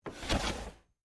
На этой странице собраны уникальные звуки комода: скрипы ящиков, стук дерева, движение механизмов.